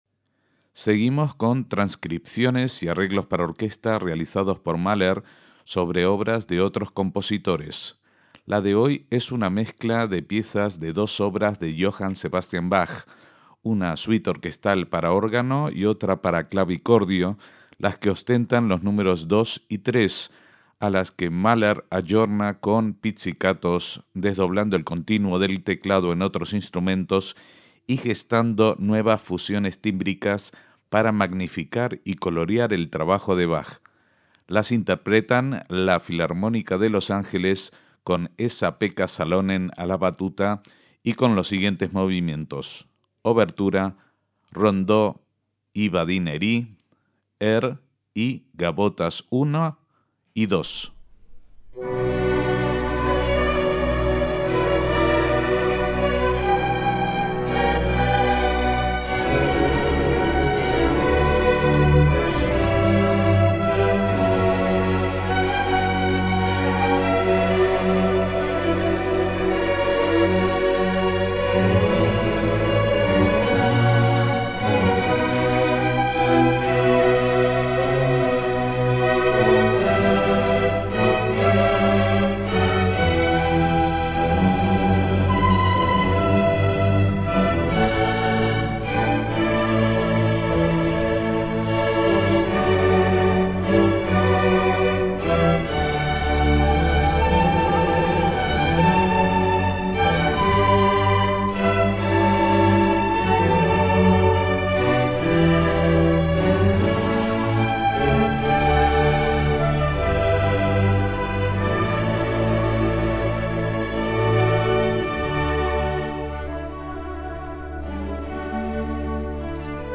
arreglando algunas de sus piezas para orquesta